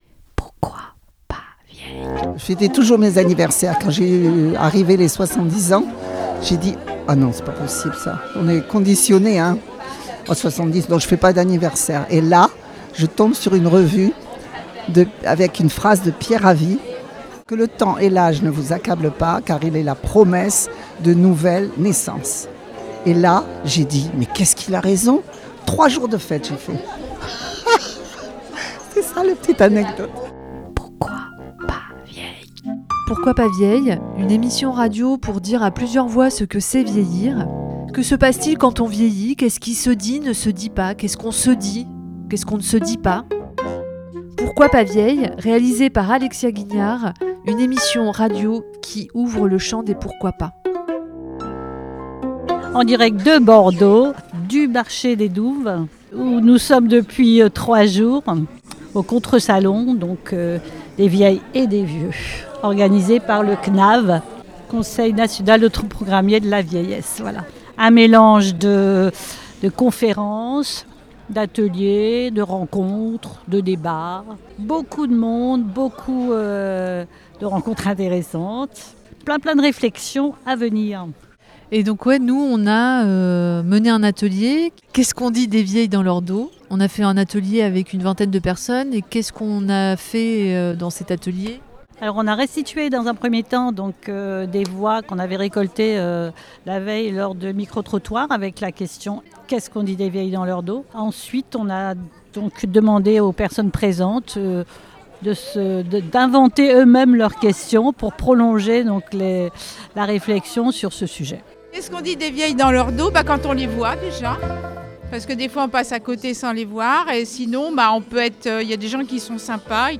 Cet épisode vous propose une brève immersion au sein du Contre Salon du CNAV qui s'est déroulé du 26 au 28 septembre à Bordeaux.
Une vingtaine de personnes ont d'abord écouté des micro-trottoirs réalisés la veille au gré des rencontres dans les allées du marché des Douves. Dans un deuxième temps, des petits groupes de 5-6 personnes ont pris le temps de la rencontre, de la discussion autour de cette drôle de question.
Il invite à une prise de distance avec cette supposée honte d'être vieille, d'être vieux. Cet exercice sous forme d'atelier radio est une proposition de mise en résonance des expériences individuelles pour construire un récit collectif.